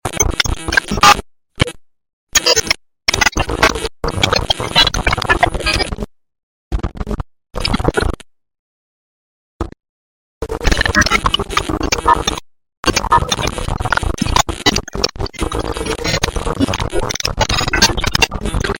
Robot glitch with sound fx sound effects free download